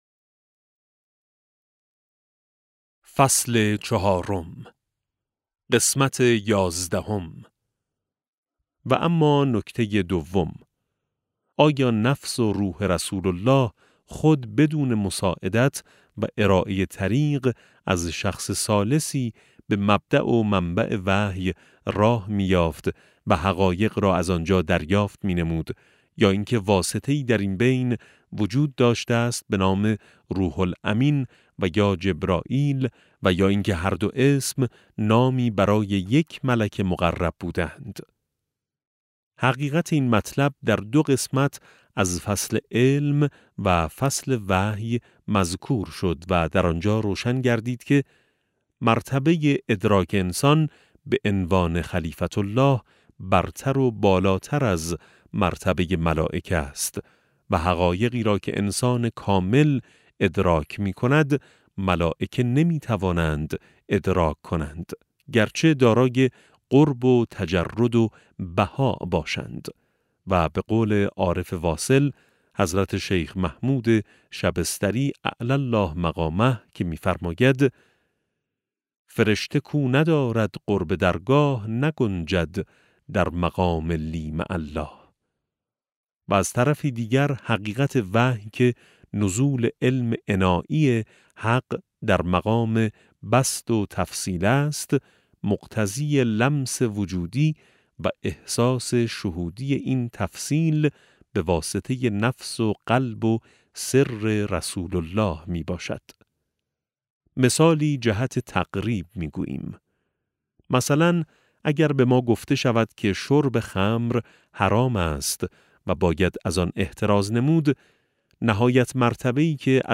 افق وحی - فصل چهارم (454 ـ 469) - کتاب صوتی - کتاب صوتی افق وحی - بخش26 - آیت‌ الله سید محمد محسن طهرانی | مکتب وحی